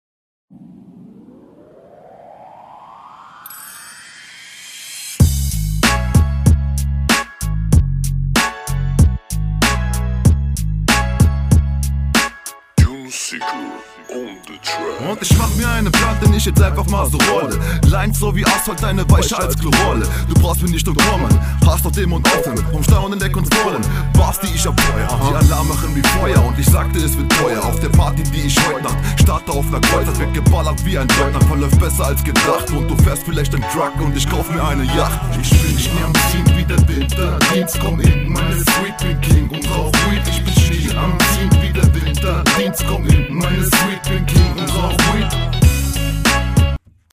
Geiler Beat schonmal, doppels sind leider nicht on point, Zweckreime vorhanden, Leider Undeutlich, das übliche …